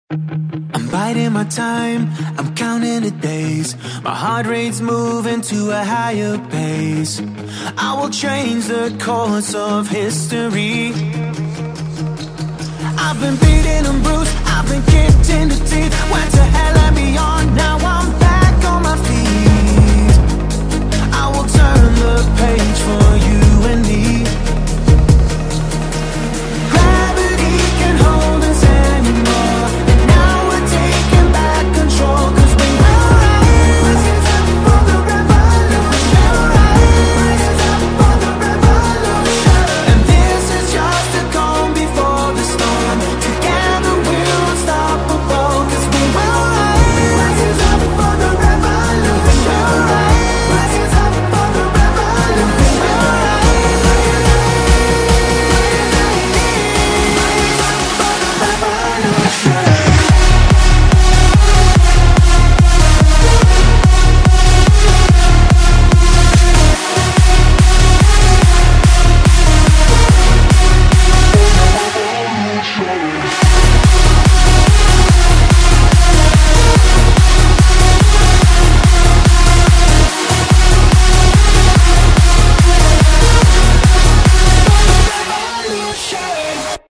BPM144
Audio QualityMusic Cut